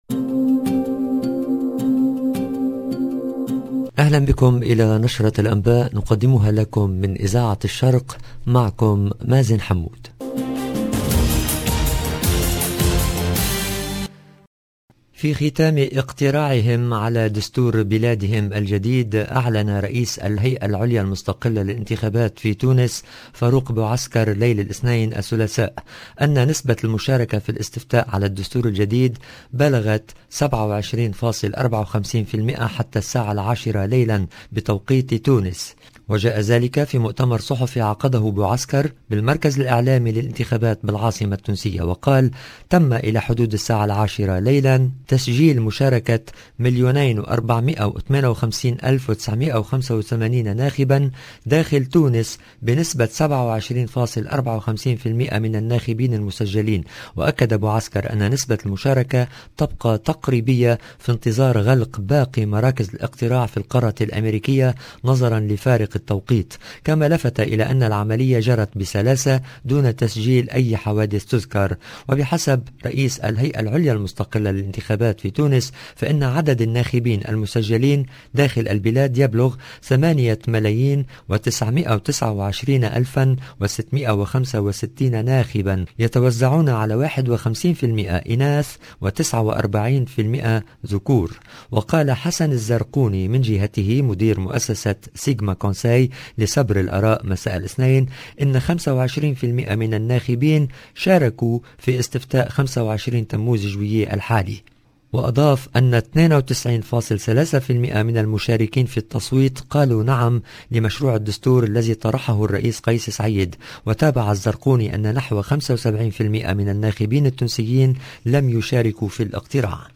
LE JOURNAL DU SOIR EN LANGUE ARABE DU 26/07/22